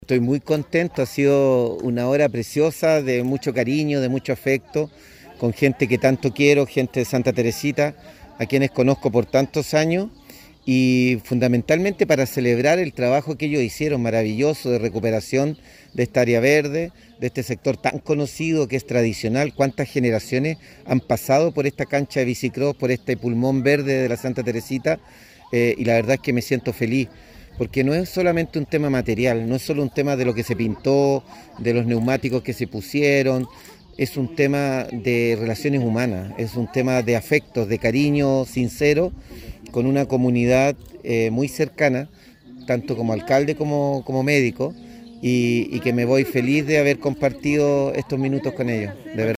Alcalde-Luis-Mella-1.mp3